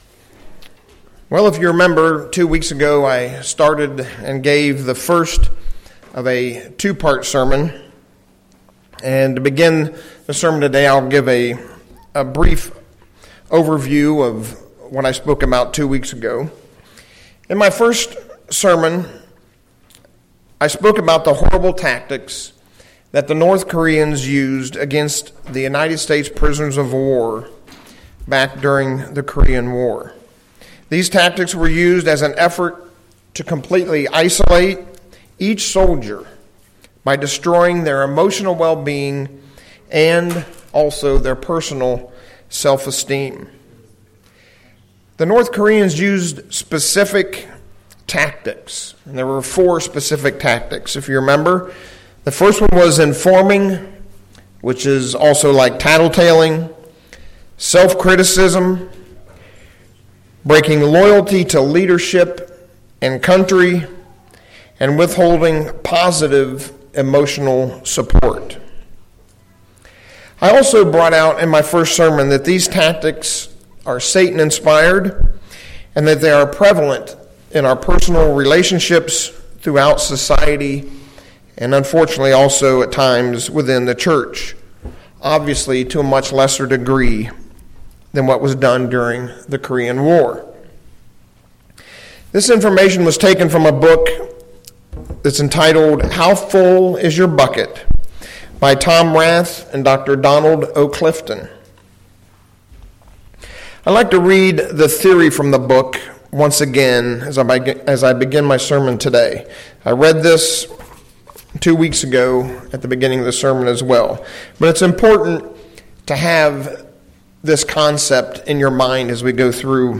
He wants us to be unbalanced, but God created us to be balanced. This sermon goes through ways to make all the difference in our emotional buckets.
Given in Ft. Wayne, IN